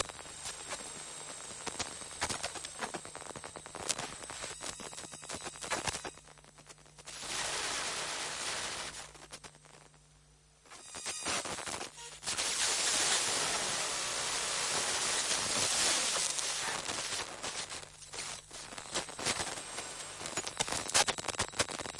拨动恐怖循环
描述：一个快乐的游戏卡通循环。滥用D和弦。
声道立体声